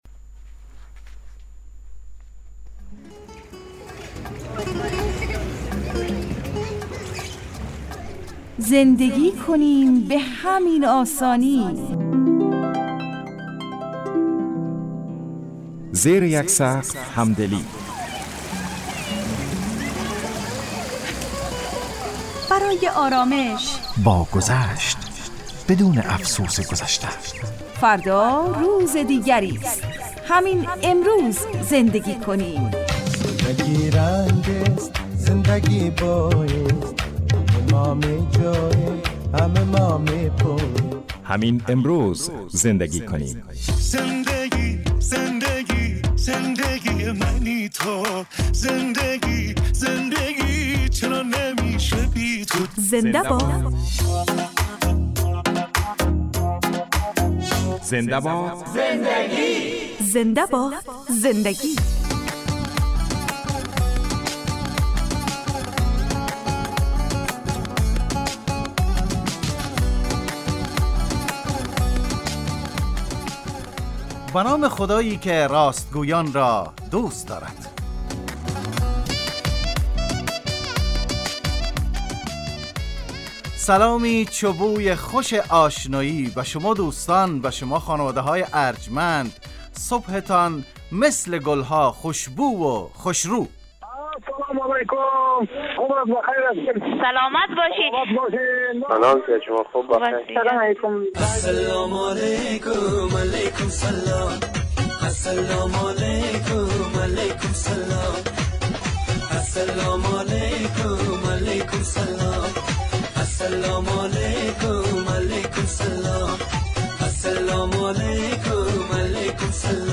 برنامه خانوادگی رادیو دری